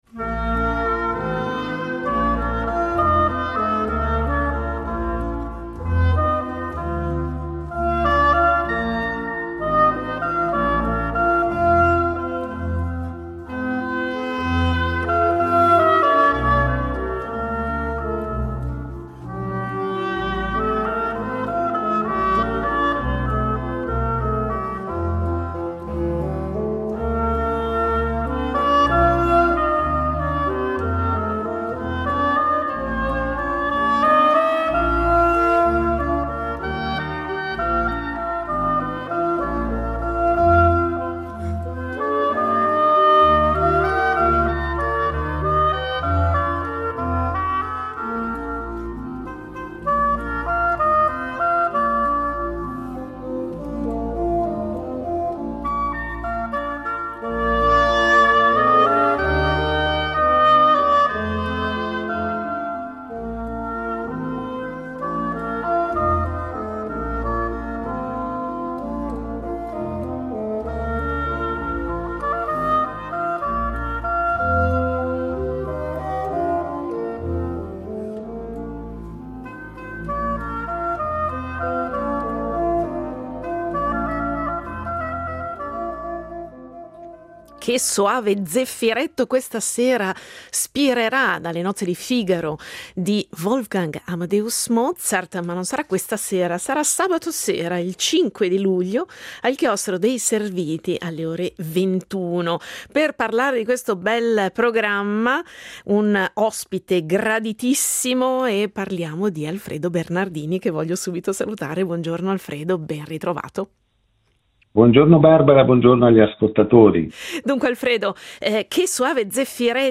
L’Ensemble Zefiro per i Concerti dei Serviti di Mendrisio